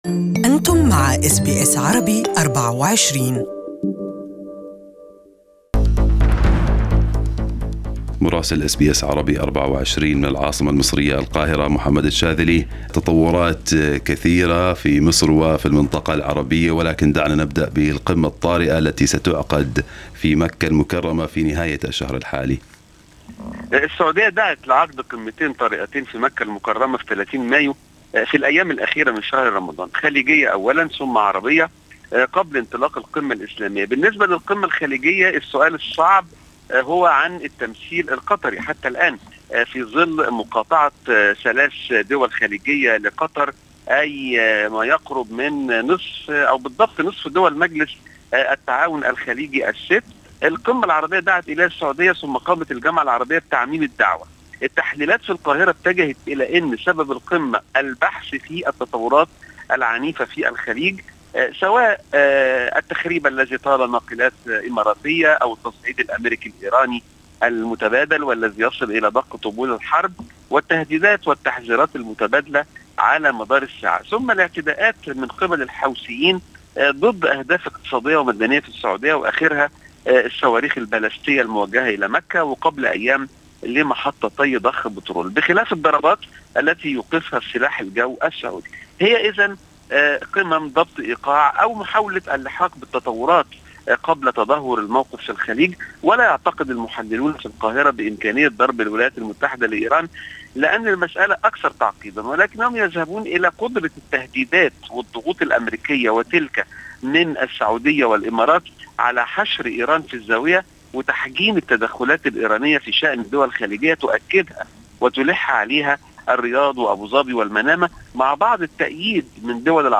Our correspondent in Egypt has the details